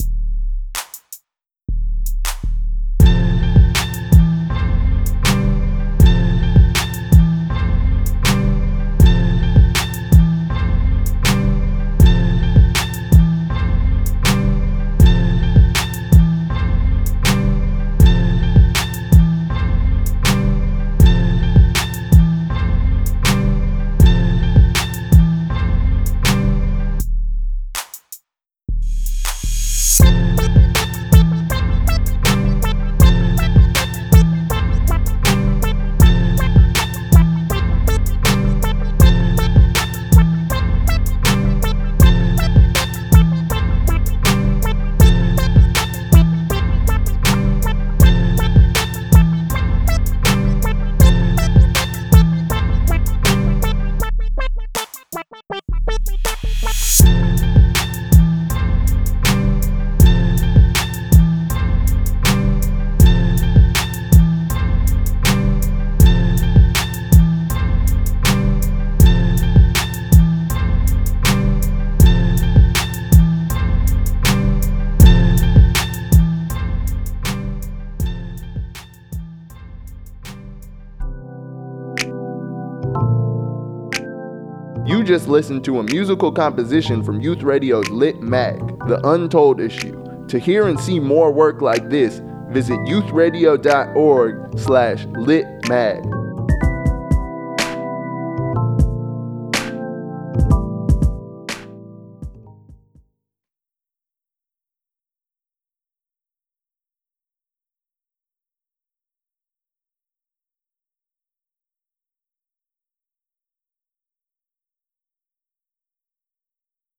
This musical composition was created using the software program Reason.